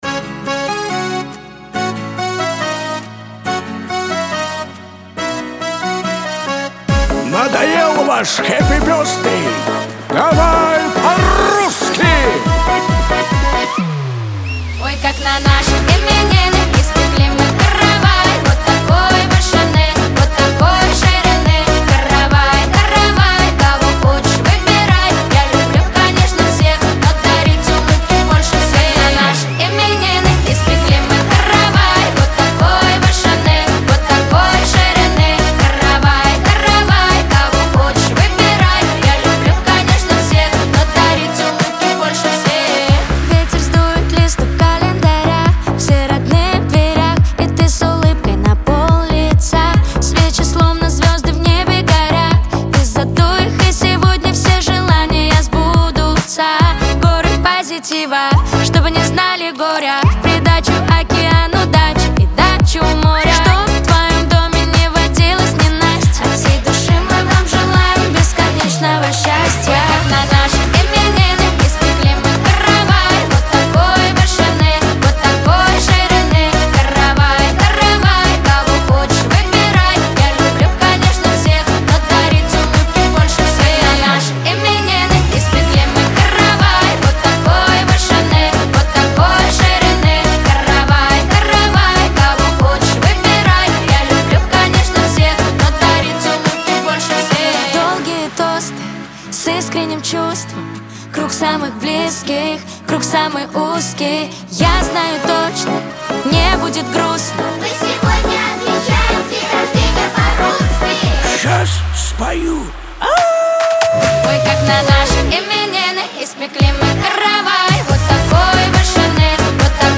Кавер-версия
pop
эстрада